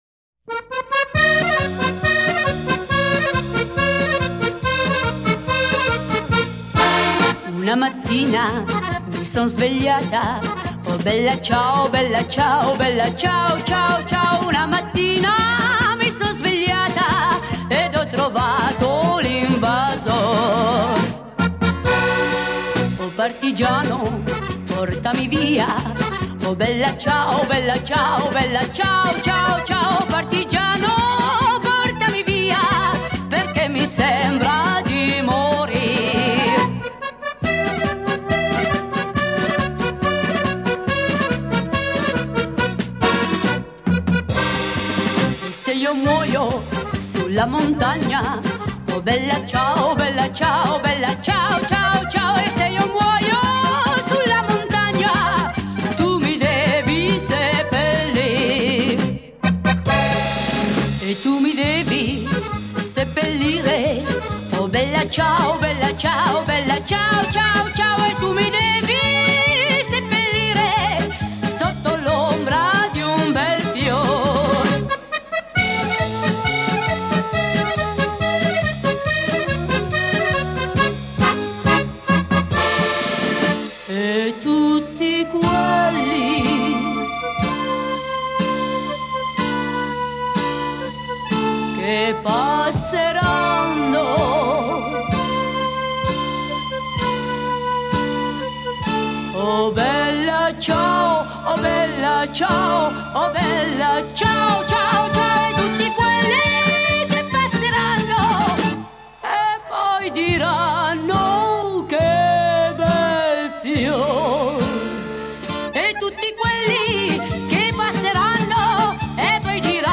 Folklore siciliano, tarantella